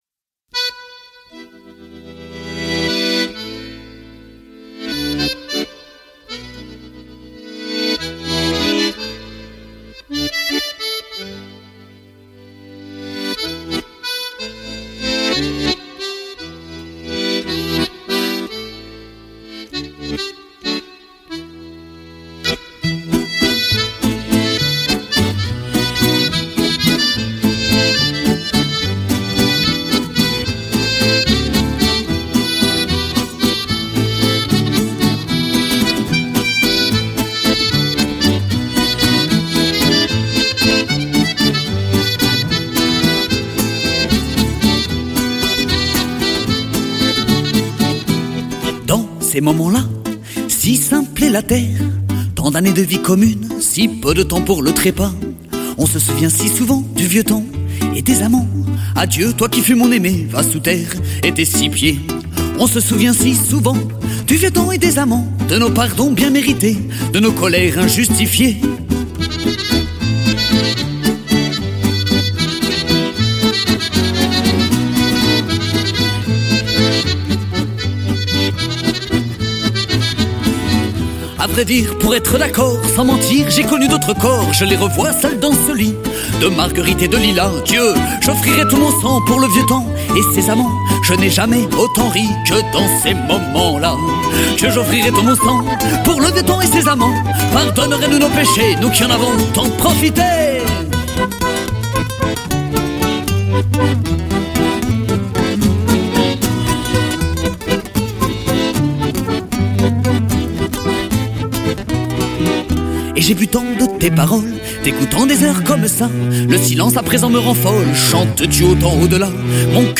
14 - Vieux temps (Chant).mp3